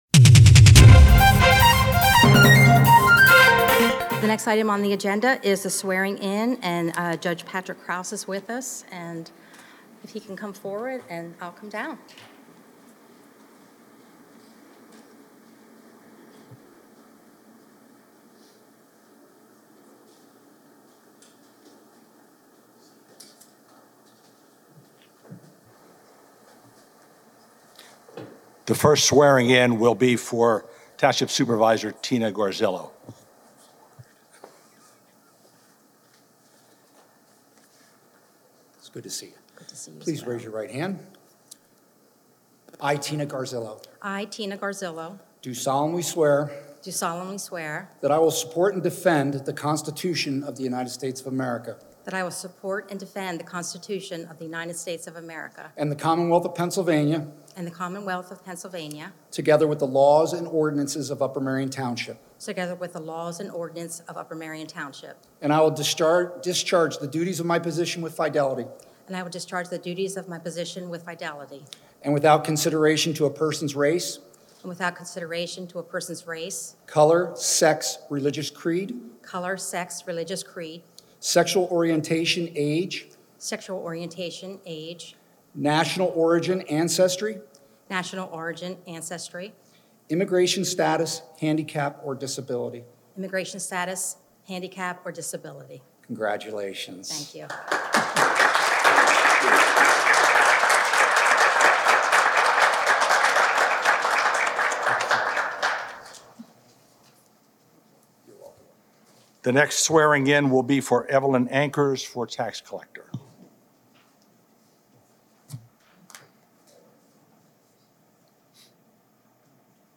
Swearing in by Judge Patrick Krouse